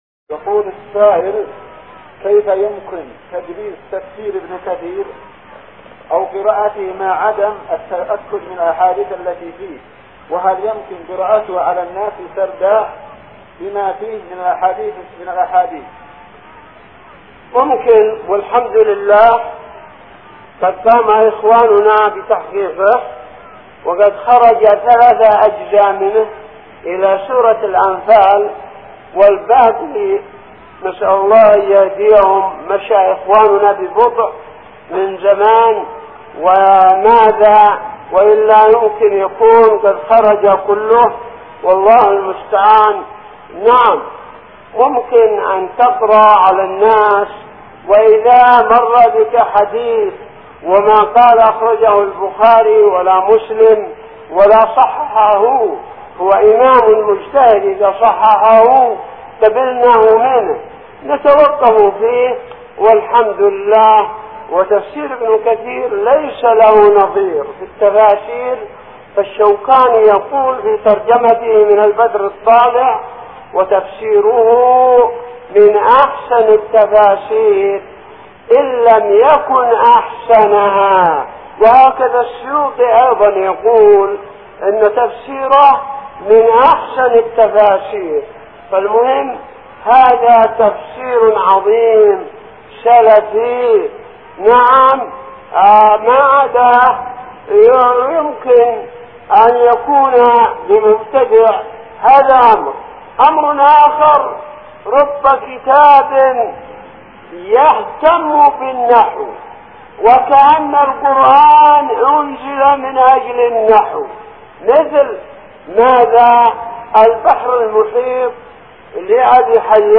------------- من شريط : ( أسئلة محاضرة مسجد العيسائي في تعز )